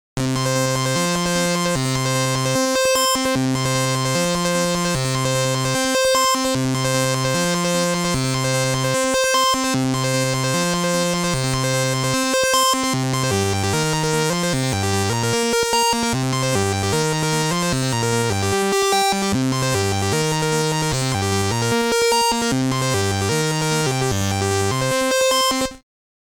The famous chip is a mixture of digital and analogue technology with phase accumulated oscillators and analogue multimode NMOS filter.